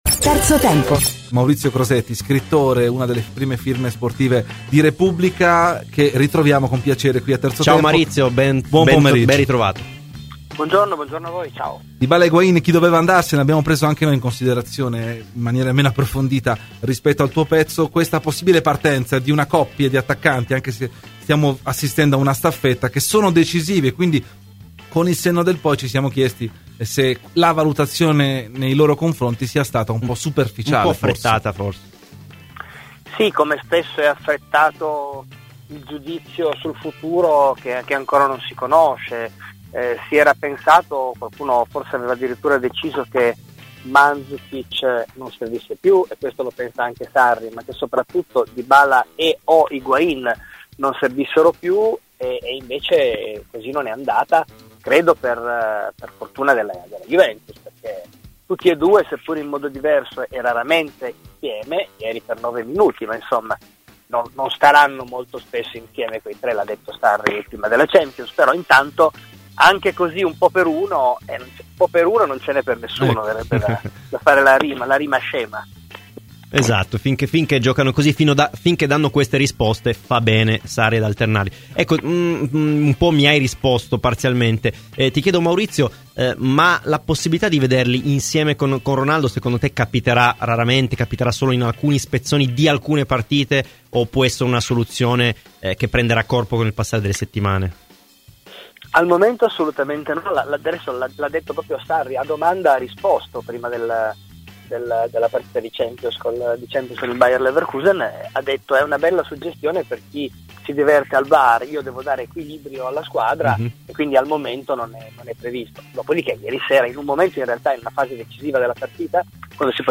ha parlato ai microfoni di Radio Bianconera nel corso di ‘Terzo Tempo’, analizzando così Inter-Juventus